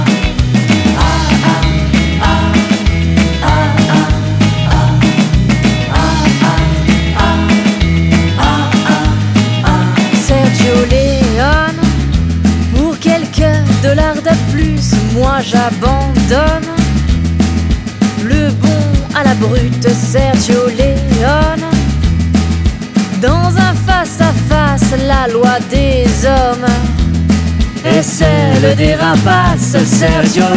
Le son est chaud, les deux voix s'accordent, se répondent.